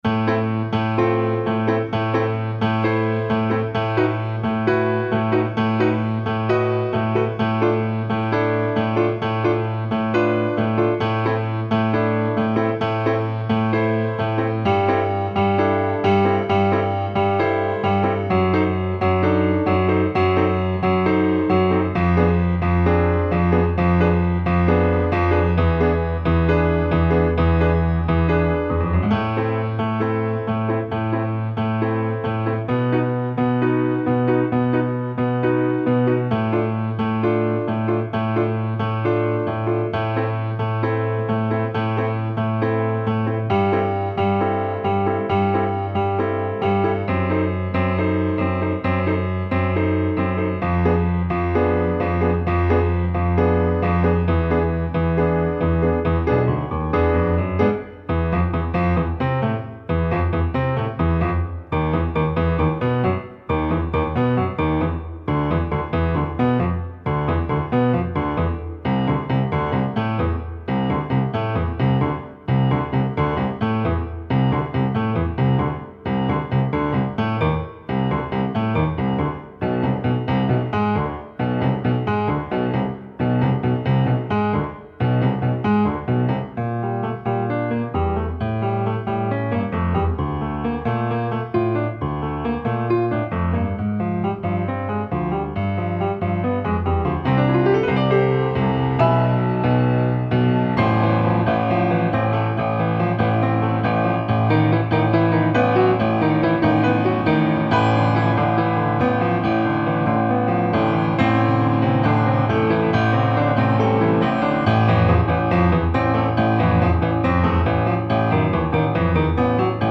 Enregistrement audio Piano seul